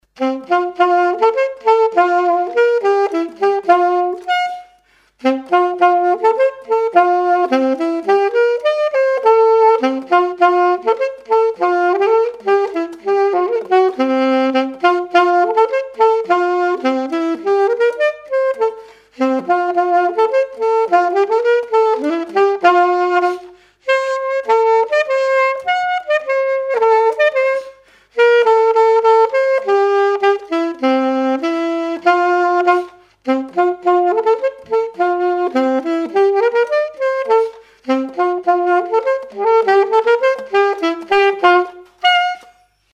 danse : quadrille : galop
témoignages et instrumentaux
Pièce musicale inédite